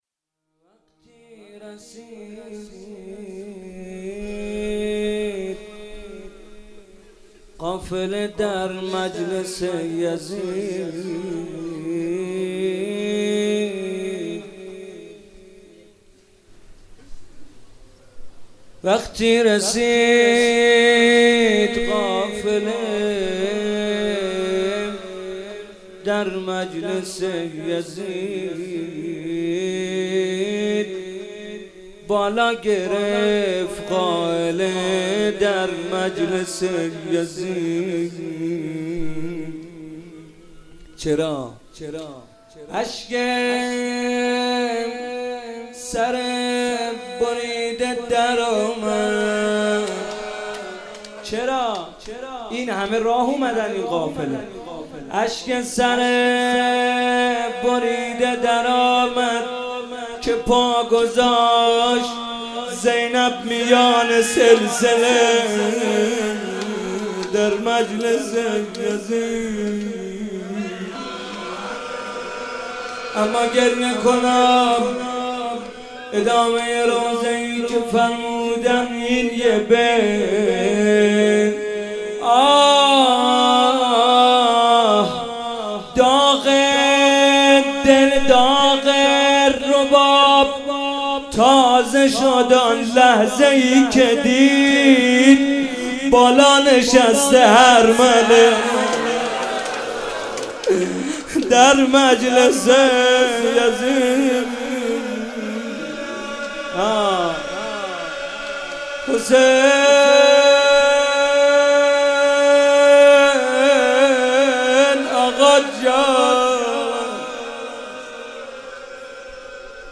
شب 25 ماه رمضان 96 - روضه - وقتی رسید قافله در مجلس یزید
ماه رمضان محمد رضا طاهری روضه مداحی